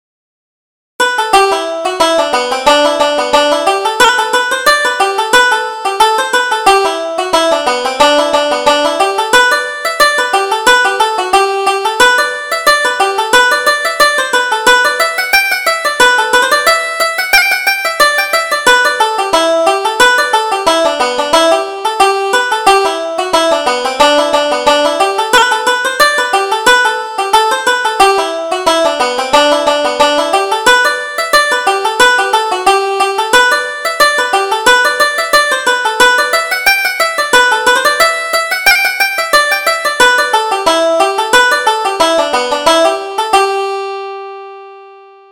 Reel: Turkies in the Straw